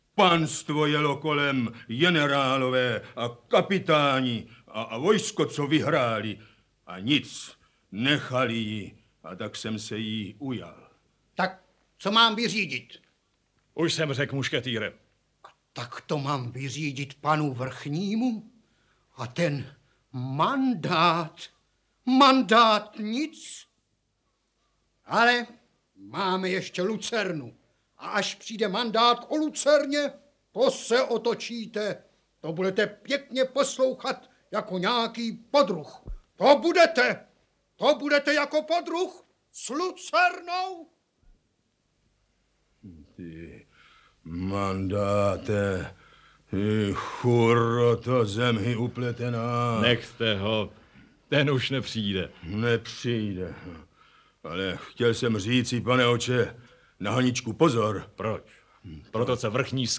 Audiobook
Read: František Filipovský